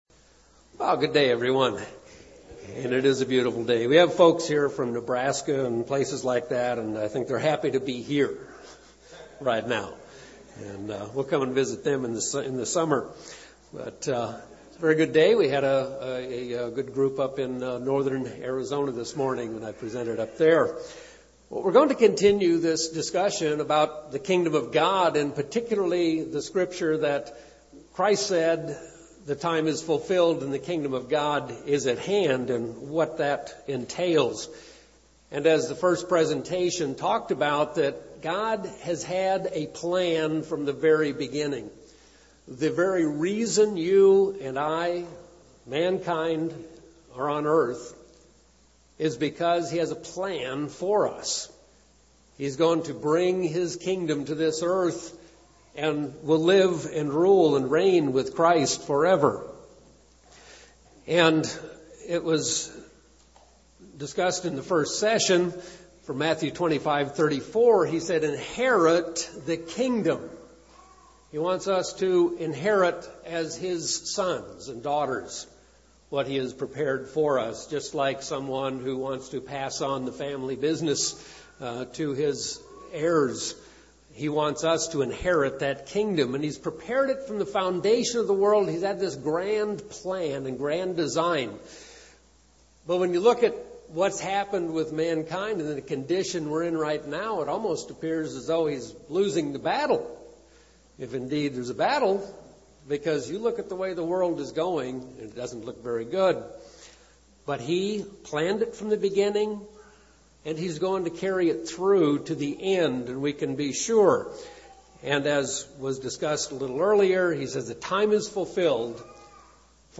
Given in Phoenix East, AZ
Print Continuing a discussion on the Kingdom of God –God has a plan UCG Sermon Studying the bible?